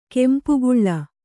♪ kempu guḷḷa